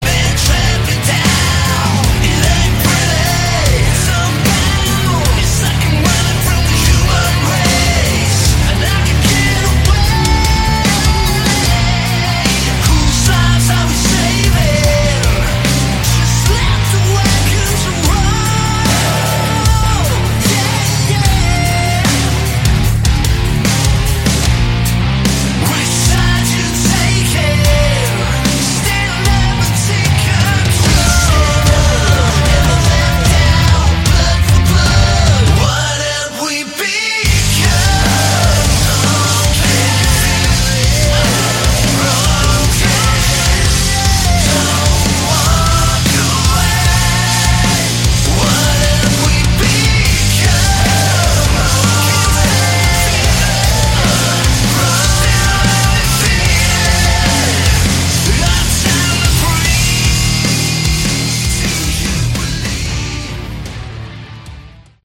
Category: Hard Rock
guitars
vocals
drums